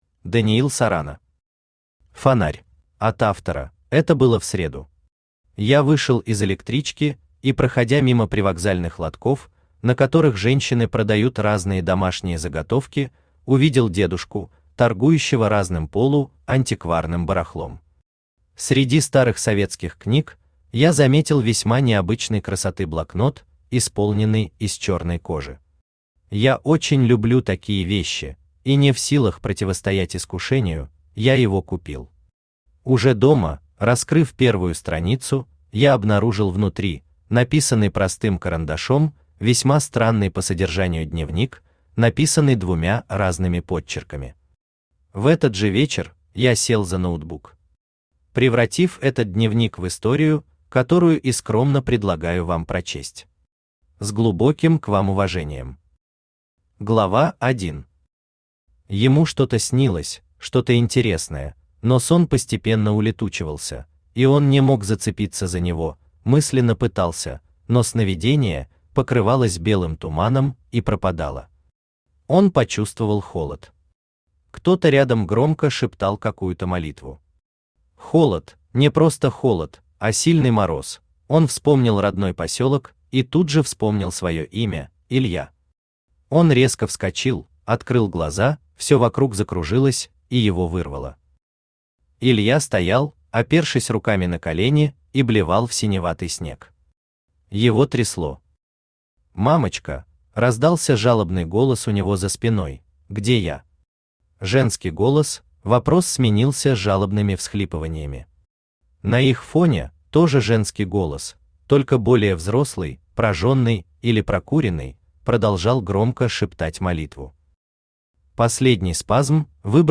Аудиокнига Фонарь | Библиотека аудиокниг
Aудиокнига Фонарь Автор Даниил Сарана Читает аудиокнигу Авточтец ЛитРес.